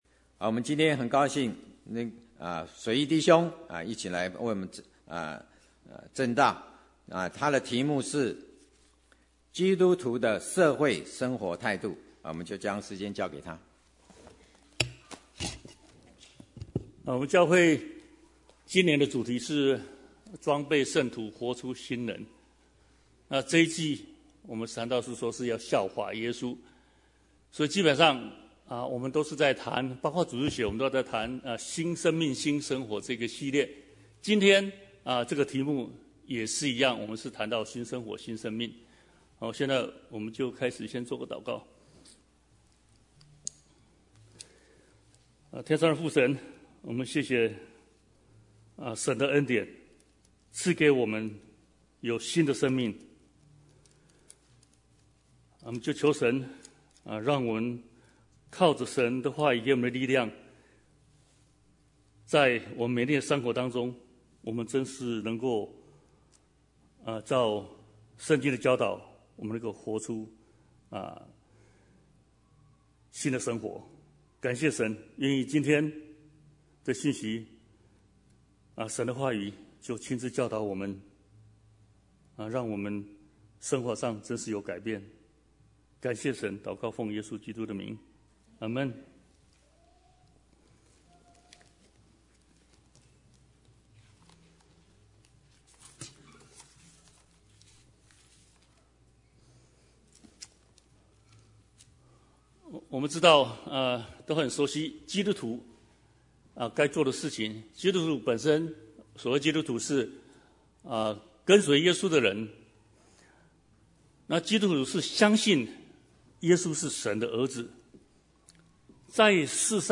Bible Text: 羅馬書 13:1-14 | Preacher